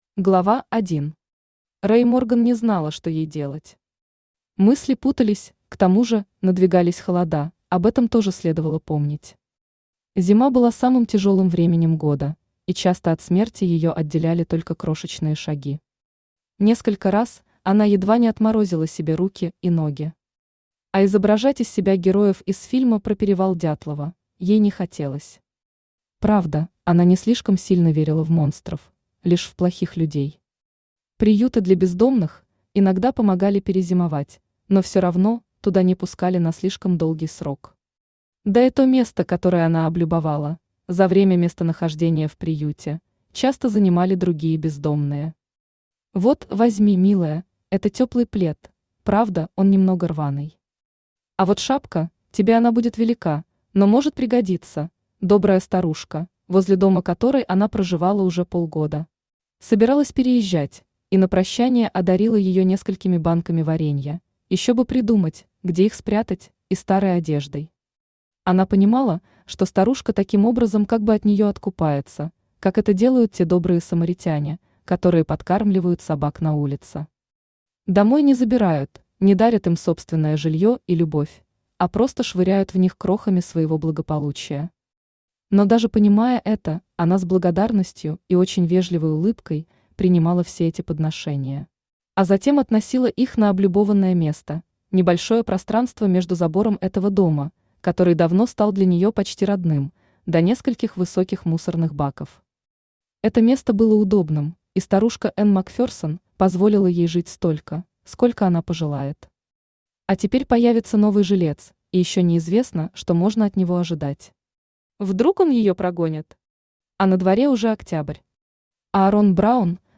Aудиокнига Золушка на свалке Автор Кристина Воронова Читает аудиокнигу Авточтец ЛитРес.